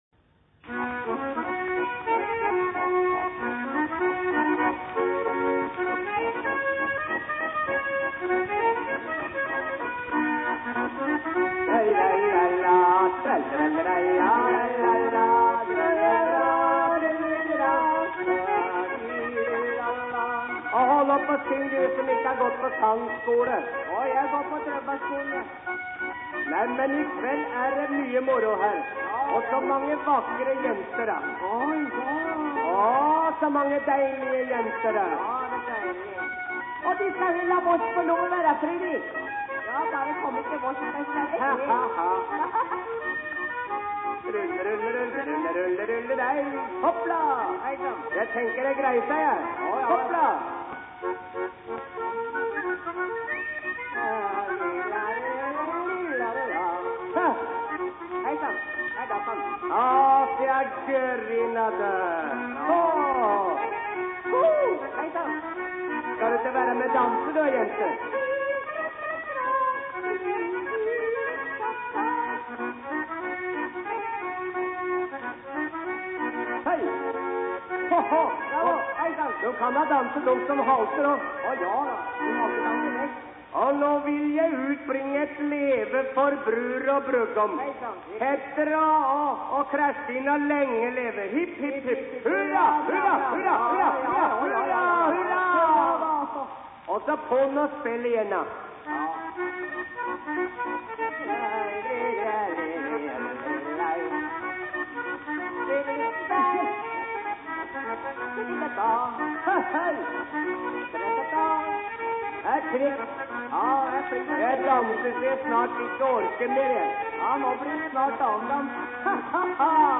trekkspilleren